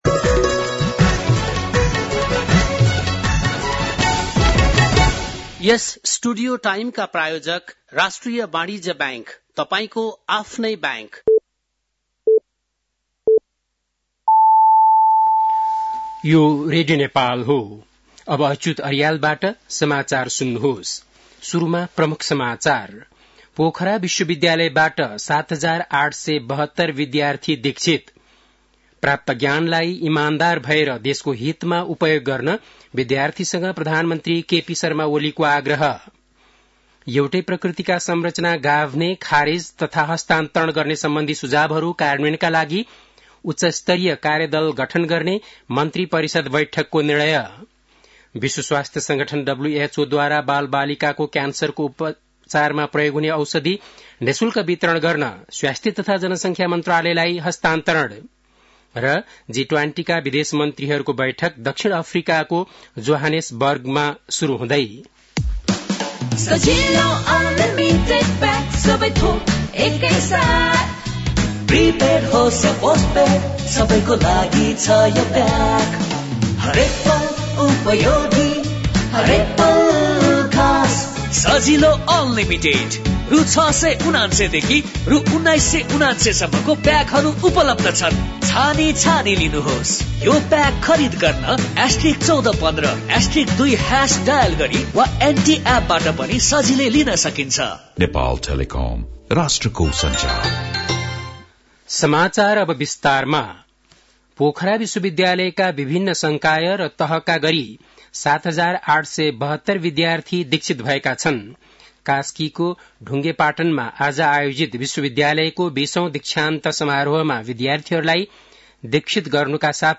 An online outlet of Nepal's national radio broadcaster
बेलुकी ७ बजेको नेपाली समाचार : ९ फागुन , २०८१
7-pm-news-2.mp3